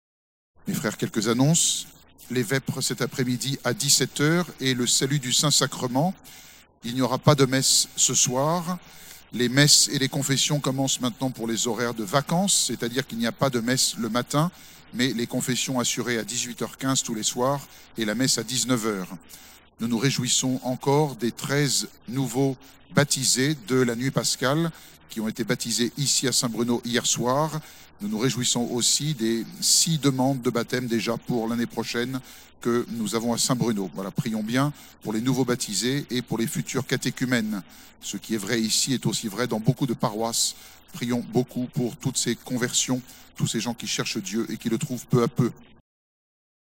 Accueil » Sermons » Veillée Pascale